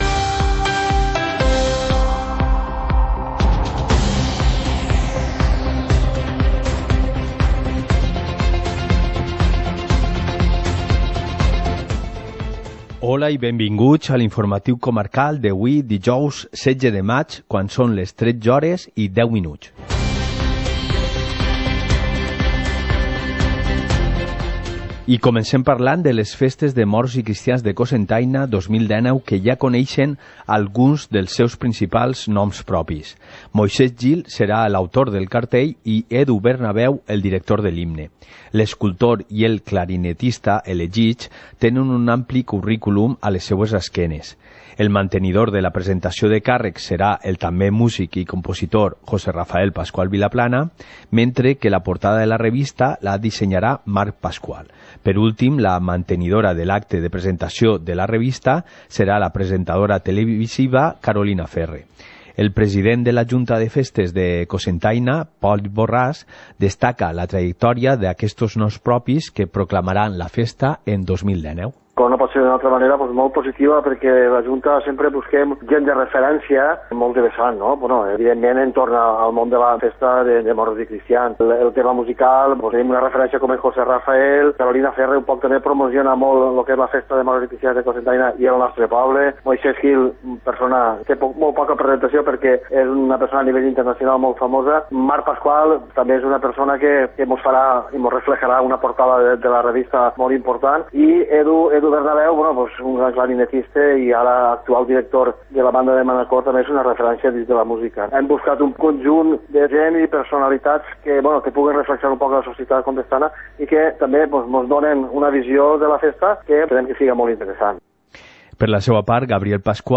Informativo comarcal - jueves, 16 de mayo de 2019